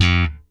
F2 3 F.BASS.wav